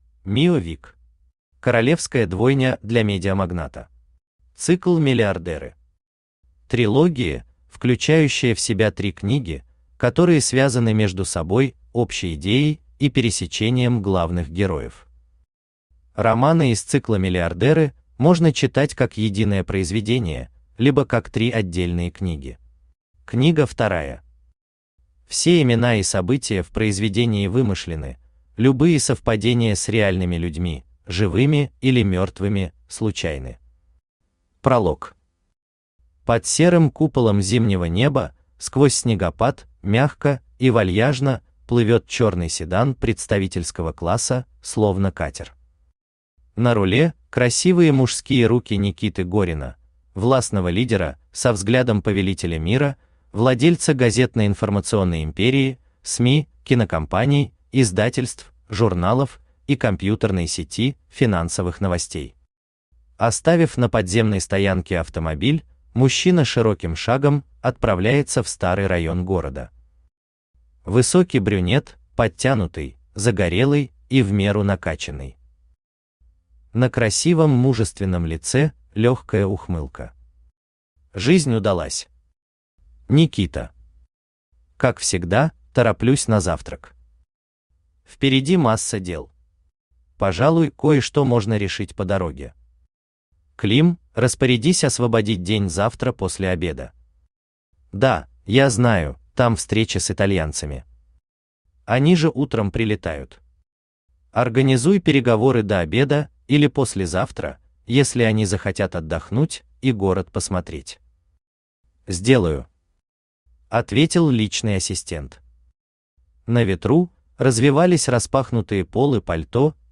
Аудиокнига Королевская двойня для медиамагната | Библиотека аудиокниг
Aудиокнига Королевская двойня для медиамагната Автор Мио Вик Читает аудиокнигу Авточтец ЛитРес.